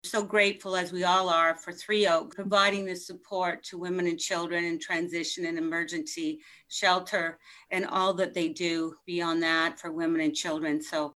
At its recent meeting, the committee recommended that Hastings County Council approve $32,000 annually for the next two years, towards a rent-subsidy program for the home.
Quinte West Councillor Karen Sharpe expressed her support for the shelter and its work for women in need of help.